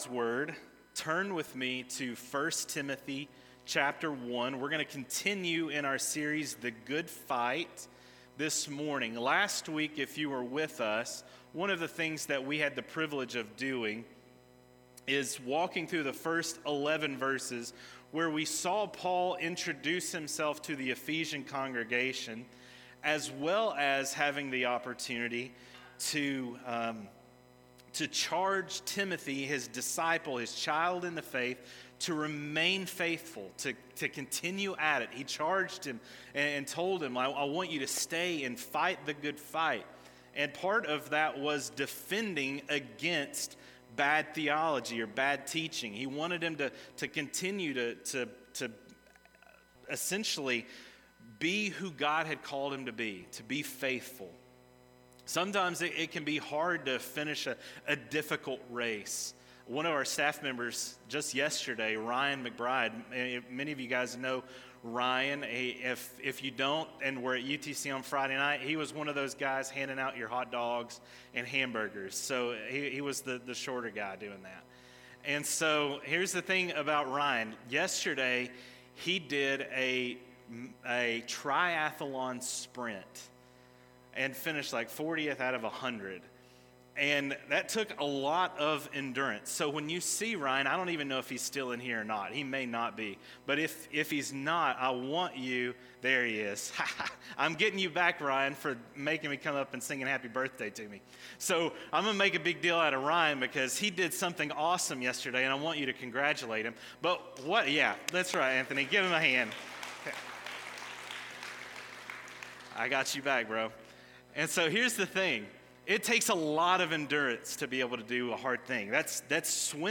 Sermons › Some Good News For Today: 1 Timothy 1:12-20